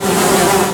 flies sound.
flies.ogg